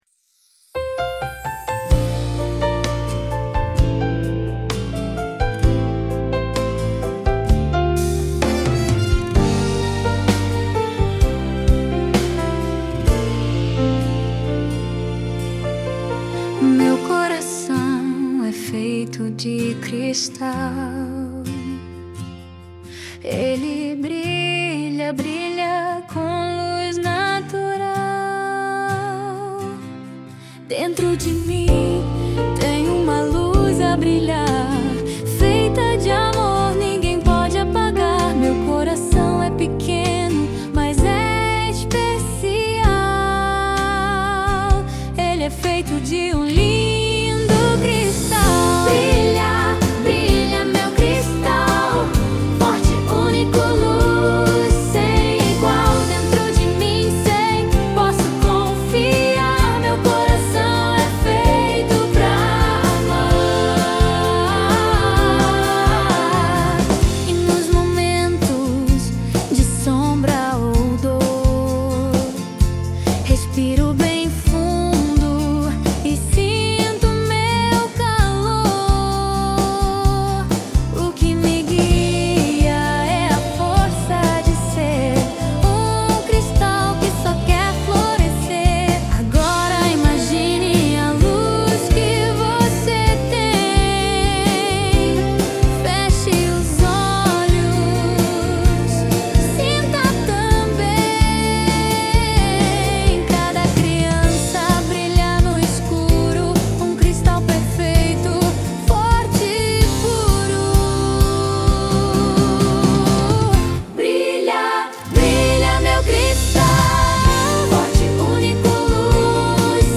EstiloNew Age